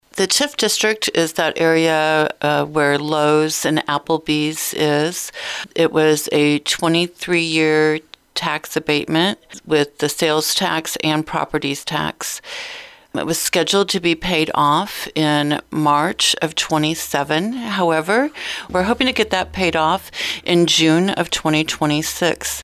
The payoff of the TIFF District in Chillicothe is expected in June of 2026.  Chillicothe City Administrator Roze Frampton says that is an early payoff.